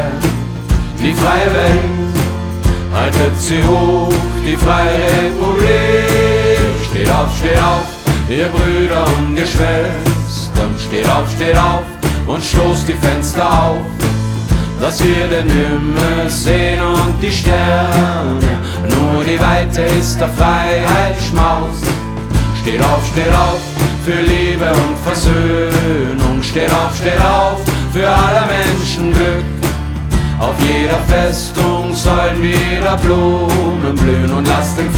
Жанр: Поп
# German Pop